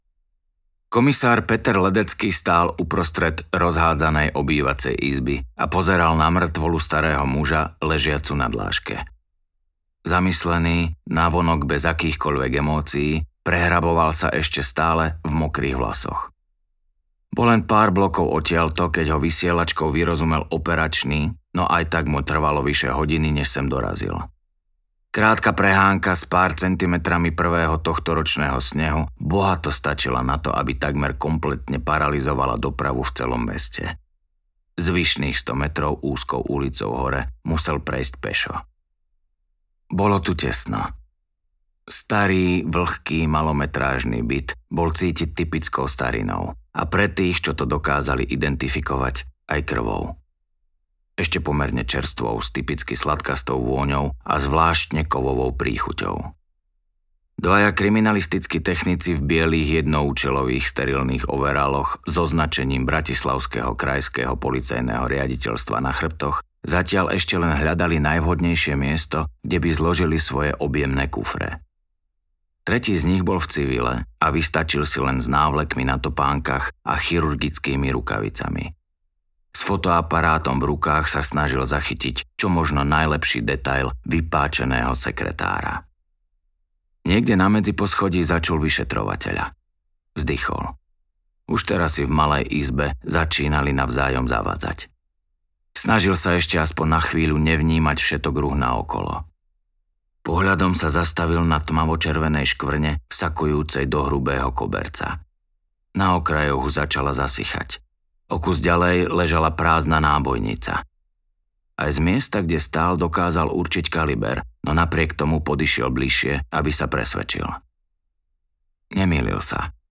Prekliate dedičstvo audiokniha
Ukázka z knihy